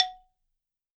52-prc07-bala-f#3.wav